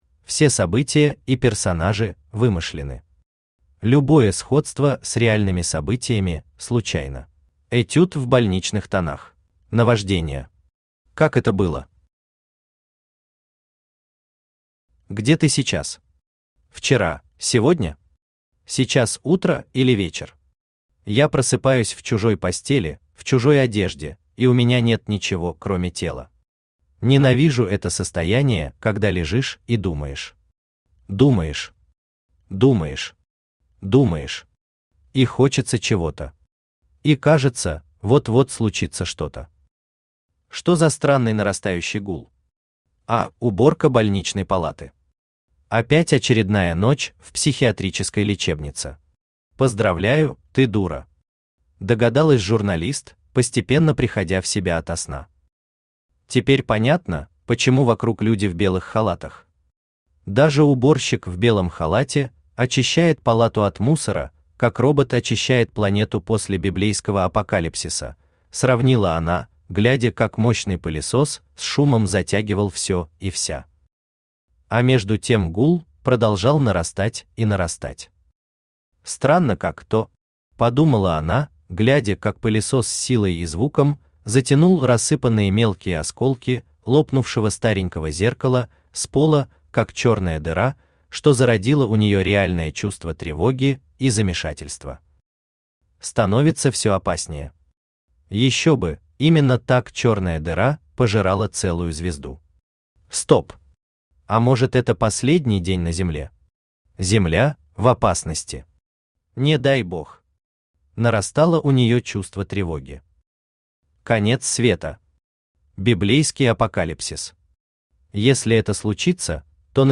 Этюд в больничных тонах Автор Юрий Павлович Шевченко Читает аудиокнигу Авточтец ЛитРес.